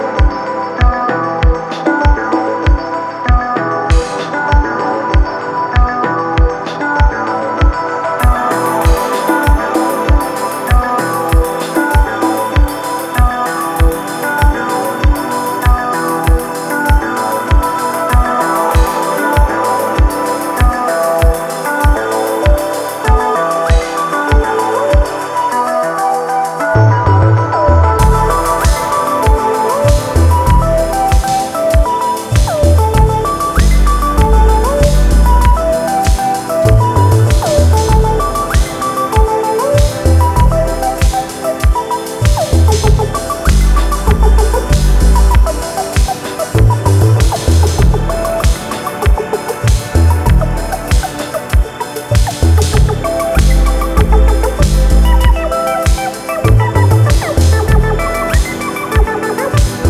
バレアリックやコズミック系DJも要チェックな個性的なサウンドがギッシリ！